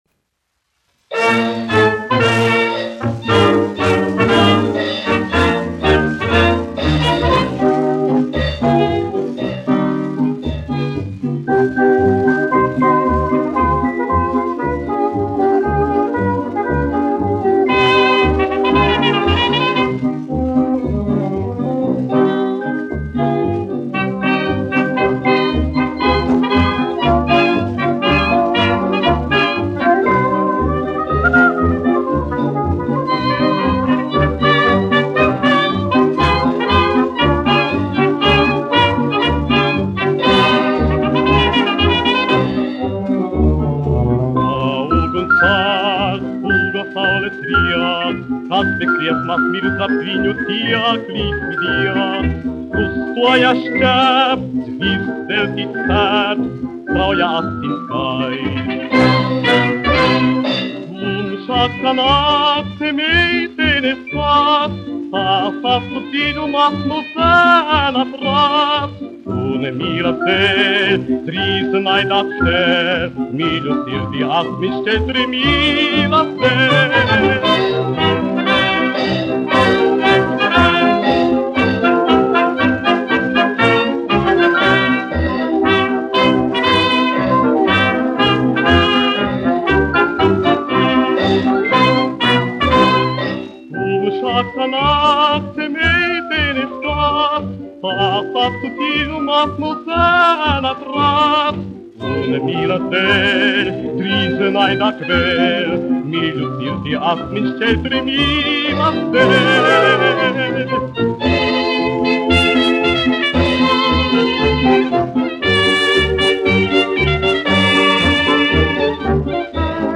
1 skpl. : analogs, 78 apgr/min, mono ; 25 cm
Fokstroti
Populārā mūzika
Skaņuplate
Latvijas vēsturiskie šellaka skaņuplašu ieraksti (Kolekcija)